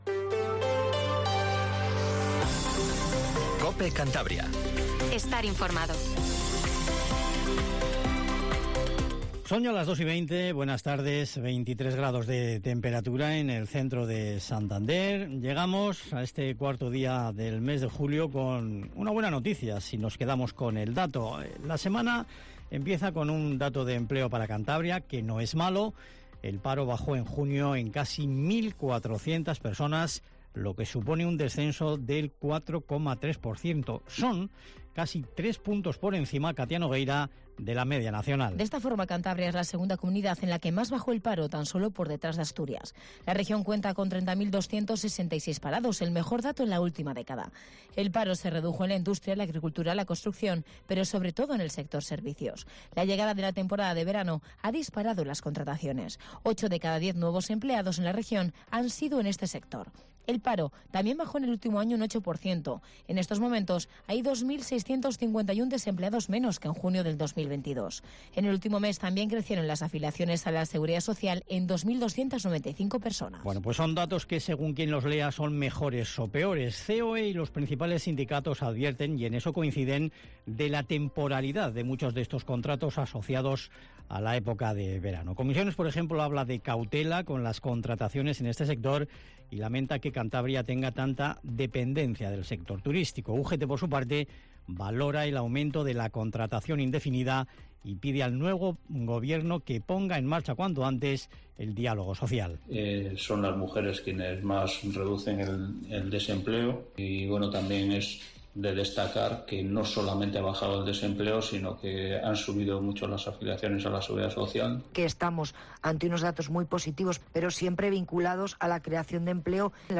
Informativo Regional 1420